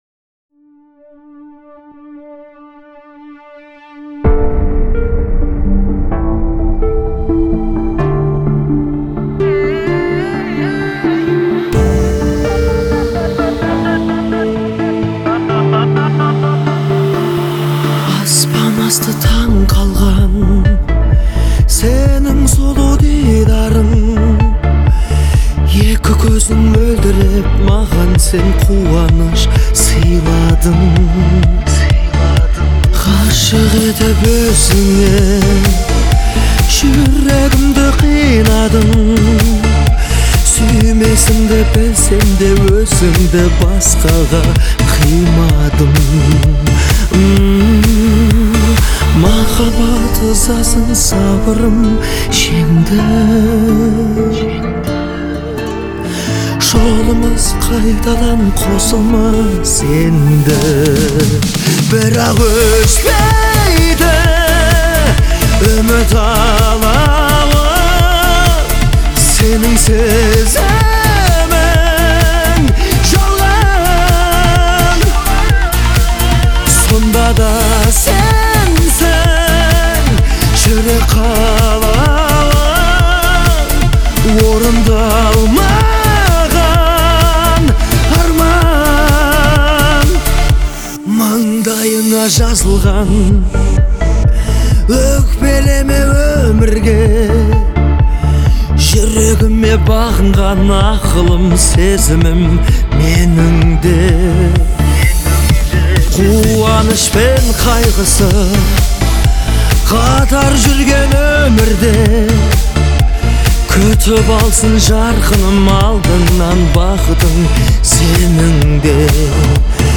это эмоциональная казахская песня в жанре поп